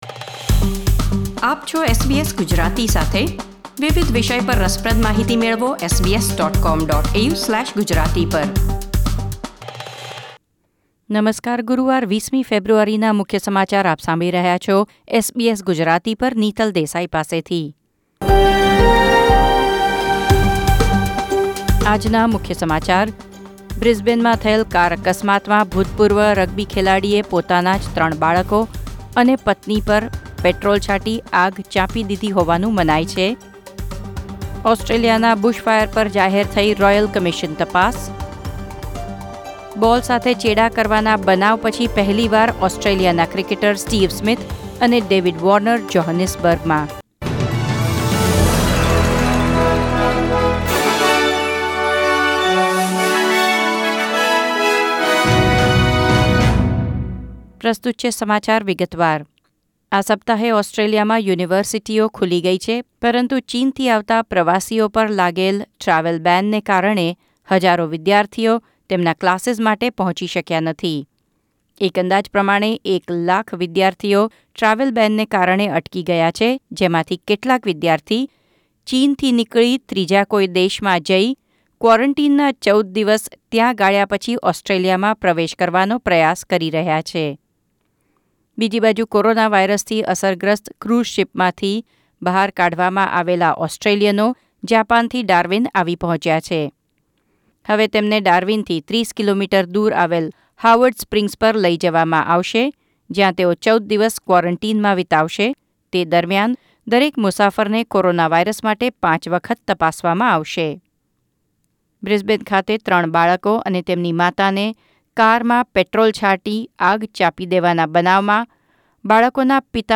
SBS Gujarati News Bulletin 20 February 2020